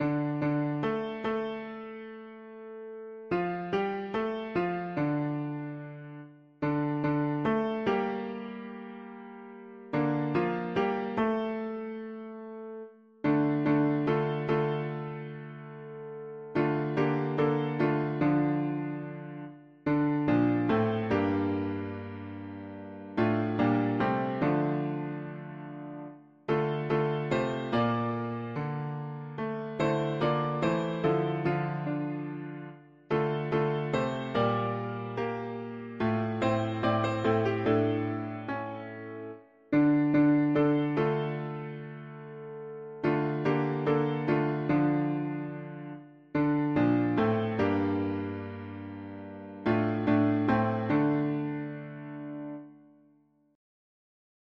… english secular 4part
Music: American traditional
Key: D minor